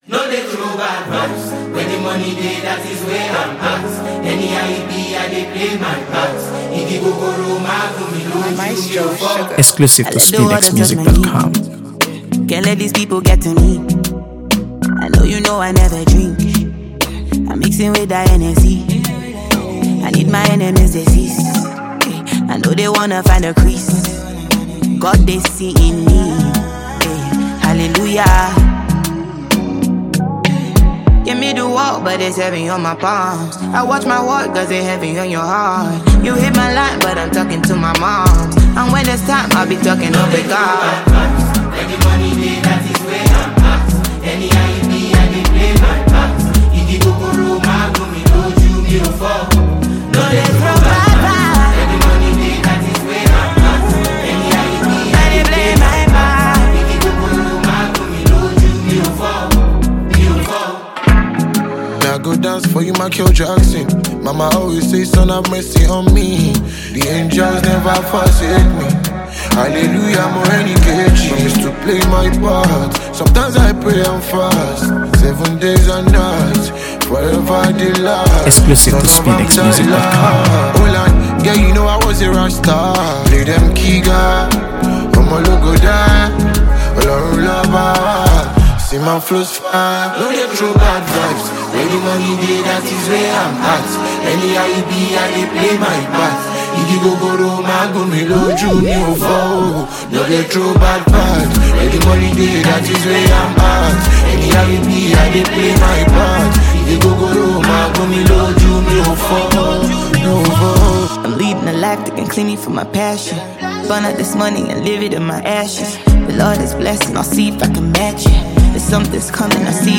AfroBeats | AfroBeats songs
featuring the soulful vocals
infectious melodies